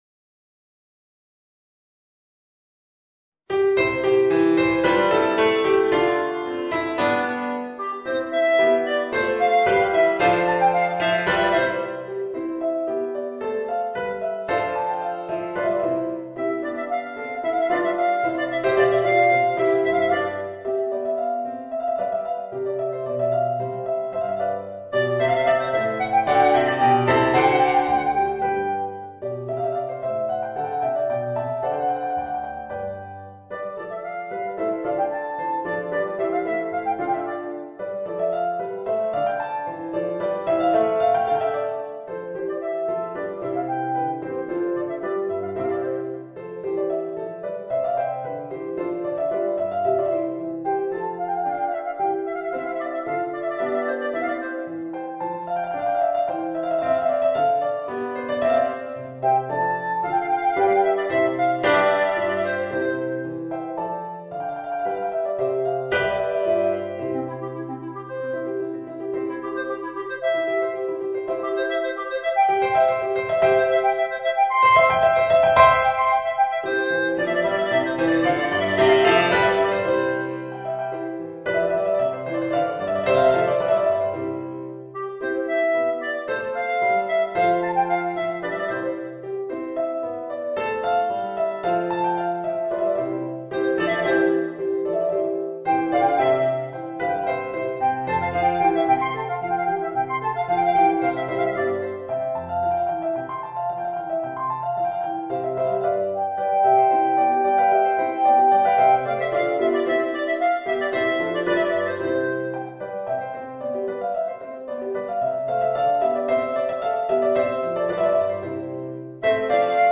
Voicing: Clarinet and Organ